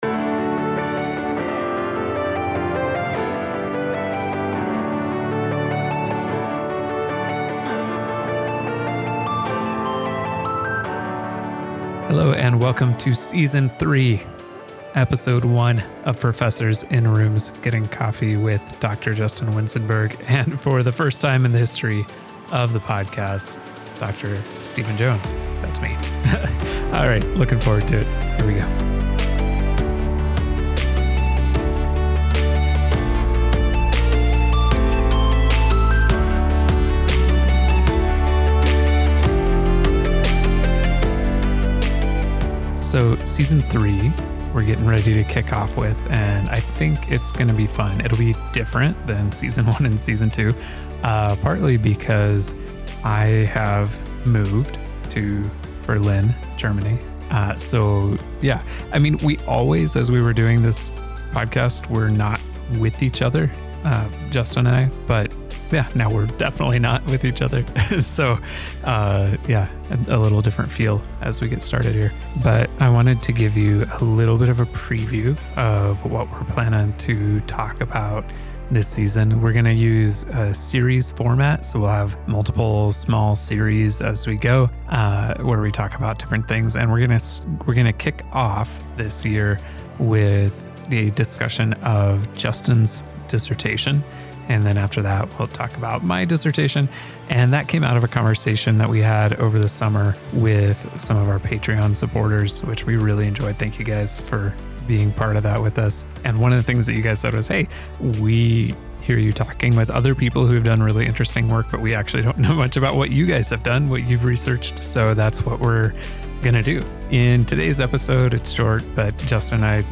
This episode was partially recorded on the land of the Wahpekute, Anishinabewaki, and the Očeti Šakówiŋ (Sioux).